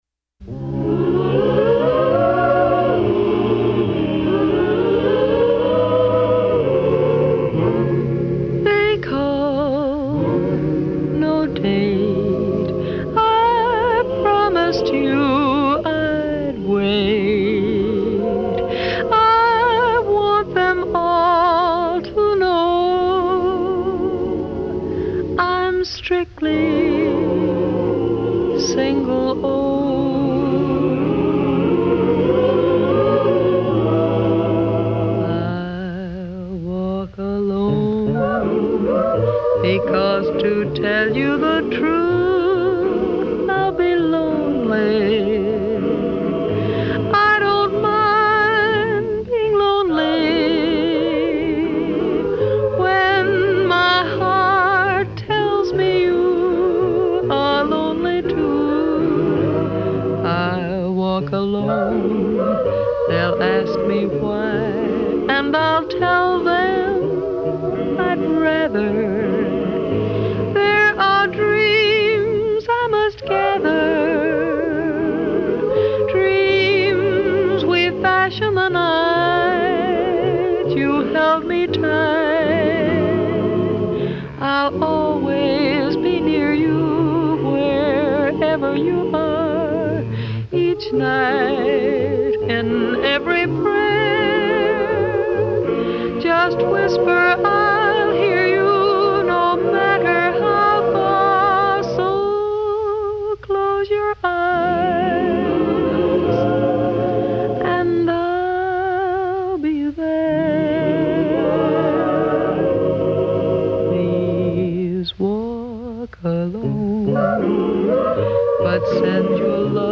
американской певицы